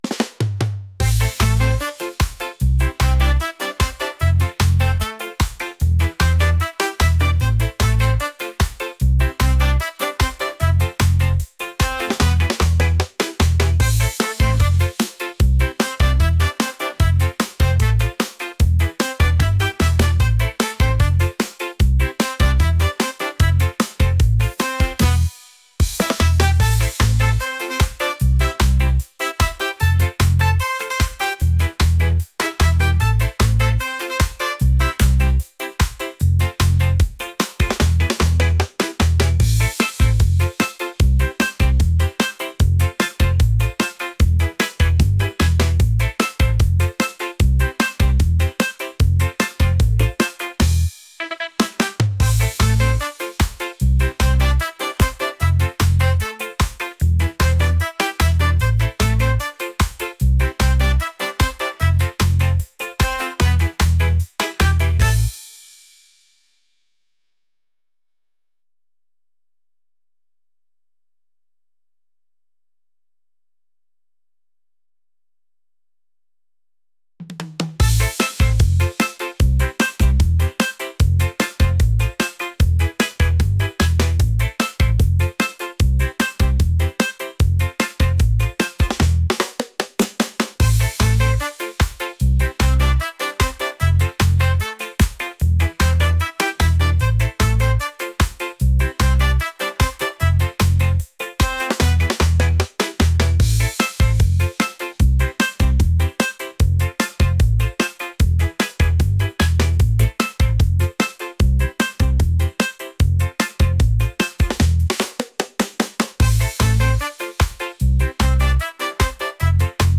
upbeat | reggae | catchy